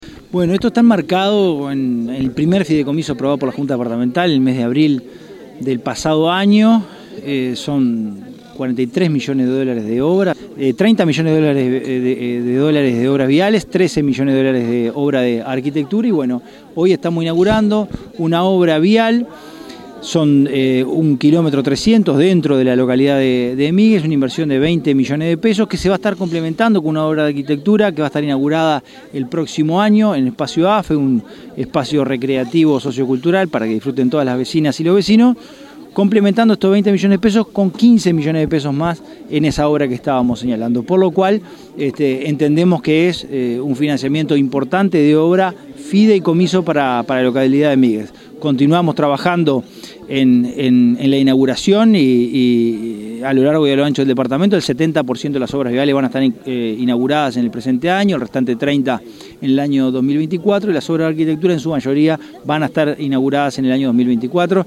Concurrieron al acto inaugural el Secretario General de la Intendencia de Canelones, Dr. Esc. Francisco Legnani, el Coordinador del Gabinete Territorial, Sergio Ashfield, el Director General de Obras, Guillermo Burgueño, el Director de Caminería Rural, Jorge Marrero, el Alcalde de Migues, Nahuel Jorge, concejales del municipio, autoridades locales, departamentales, vecinos y vecinas.